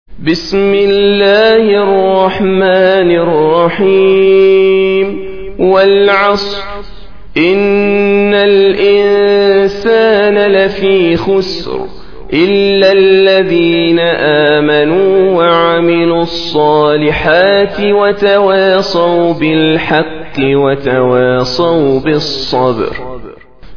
Surah Sequence تتابع السورة Download Surah حمّل السورة Reciting Murattalah Audio for 103. Surah Al-'Asr سورة العصر N.B *Surah Includes Al-Basmalah Reciters Sequents تتابع التلاوات Reciters Repeats تكرار التلاوات